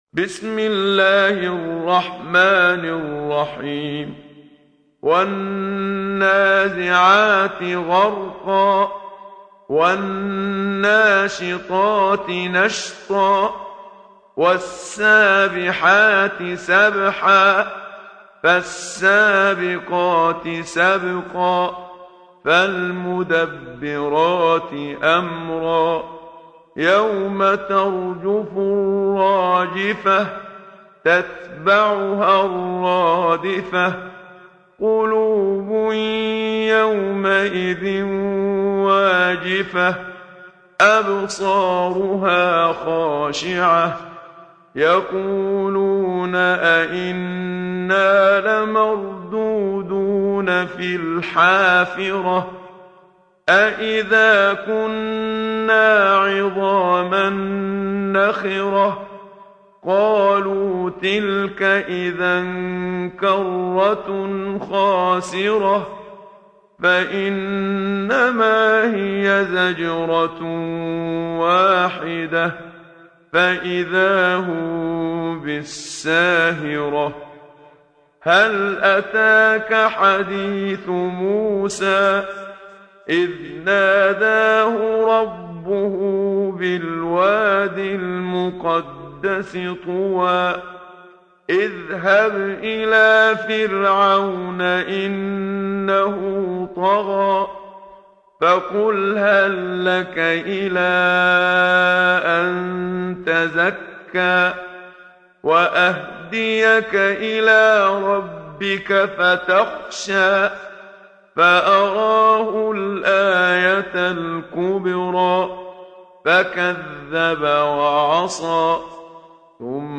سورة النازعات | القارئ محمد صديق المنشاوي